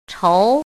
“仇”读音
chóu
chóu.mp3